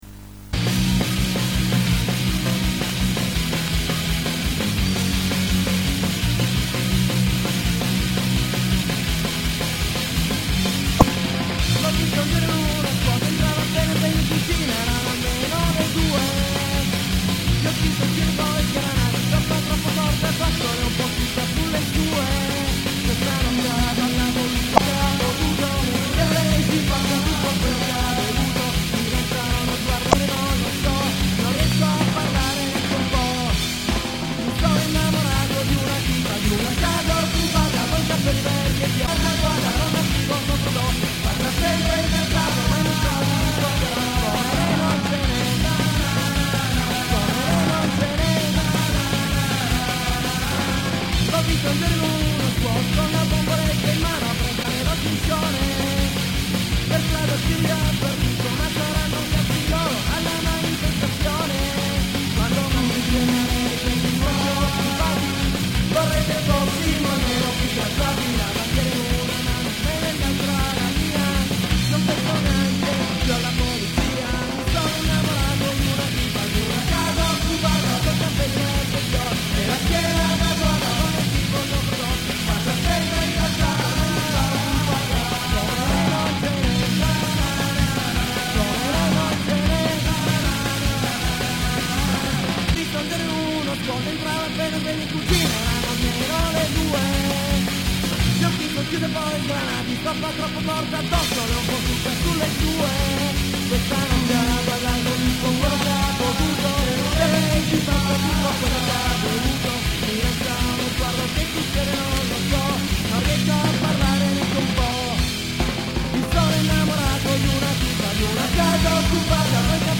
(1994, Punk rock, Torino)